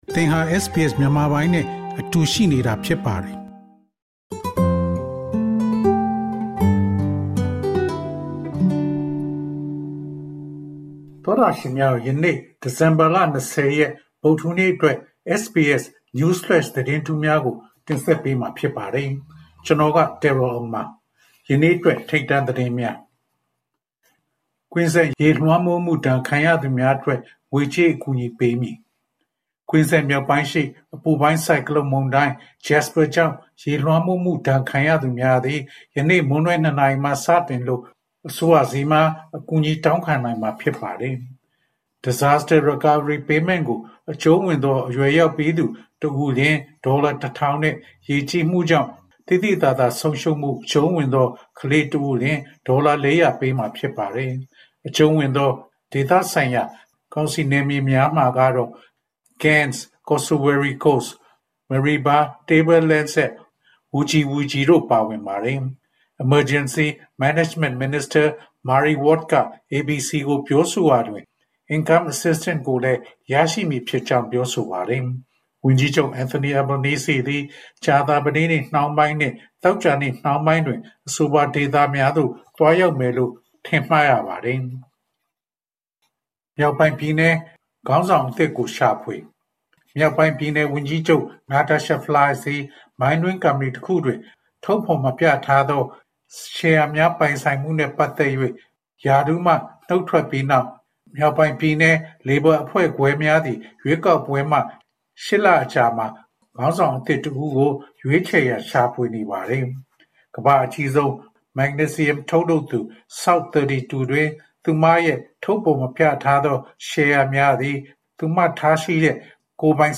News Flash